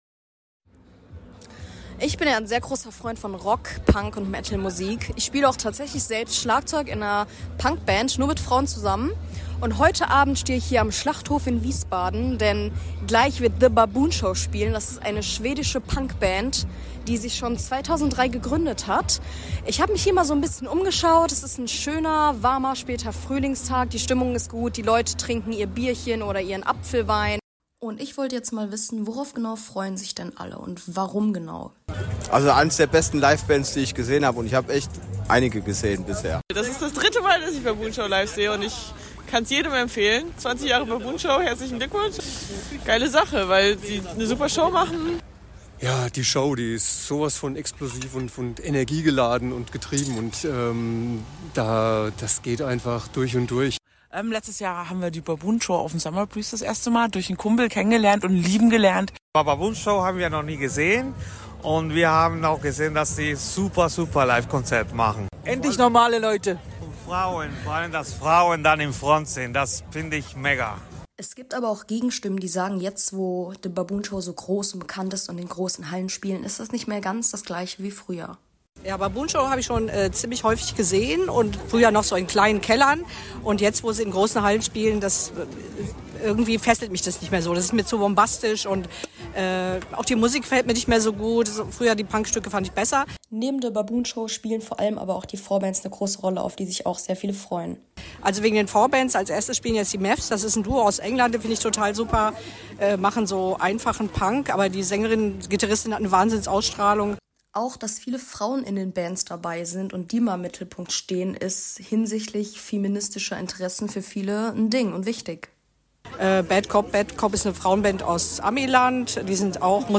Vor Ort habe ich mir ein Bild von der Freude und Stimmung der Fans gemacht – worauf sie sich freuen und wieso die Band und ihre Show sich lohnen.